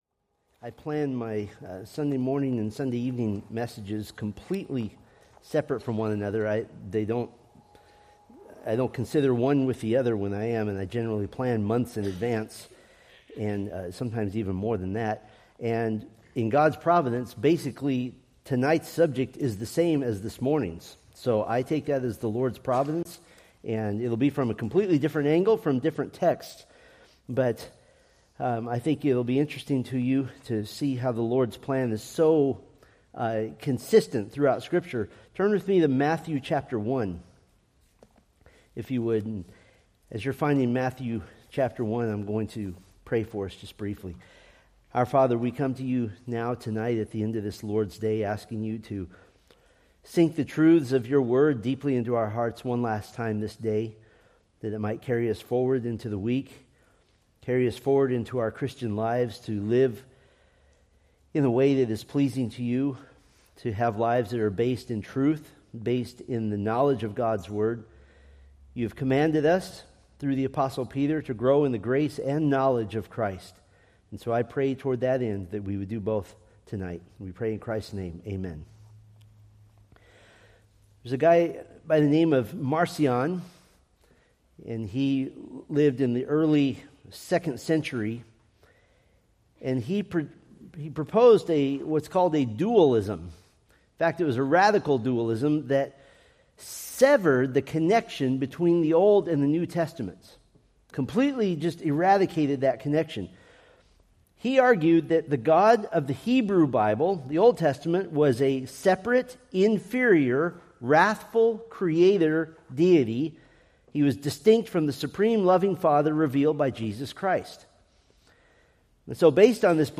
Preached December 21, 2025 from Selected Scriptures